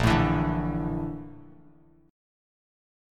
BbM#11 chord